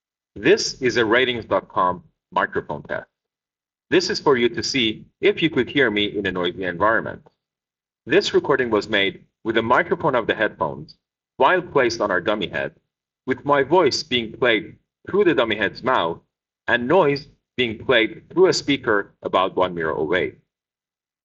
quiet and